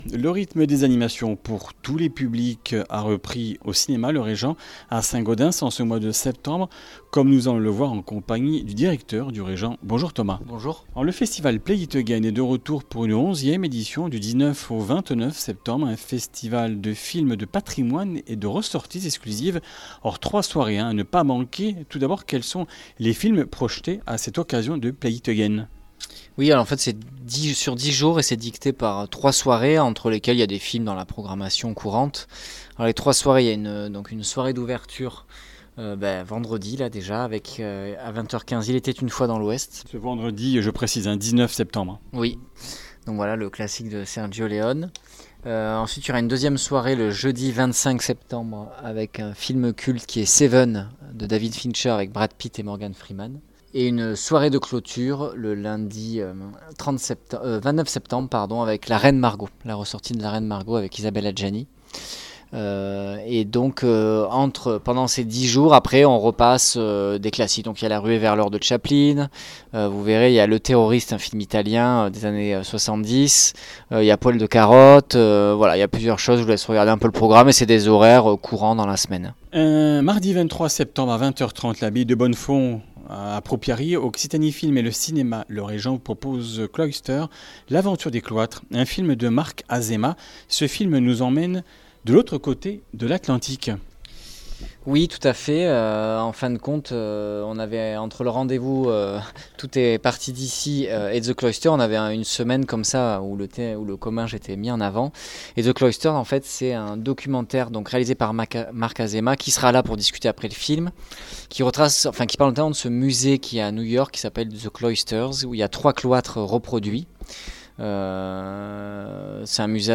Comminges Interviews du 18 sept.